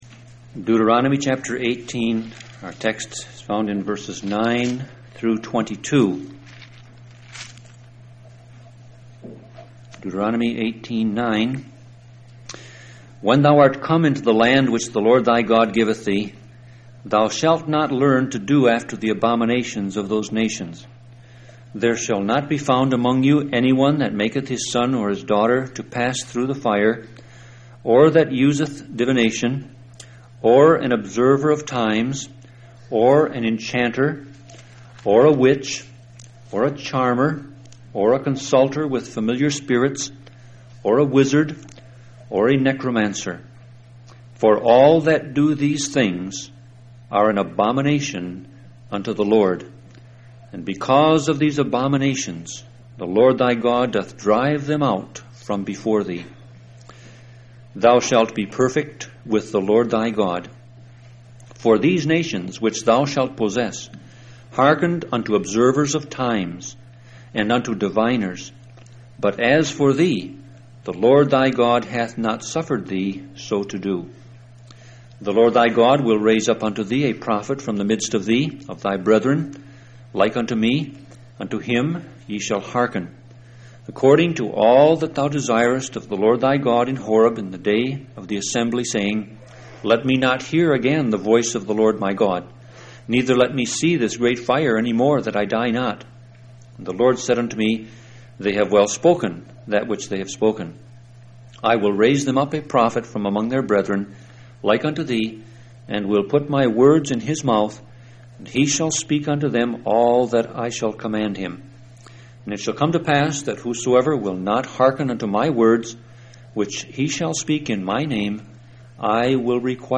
Sermon Audio Passage: Deuteronomy 18:9-22 Service Type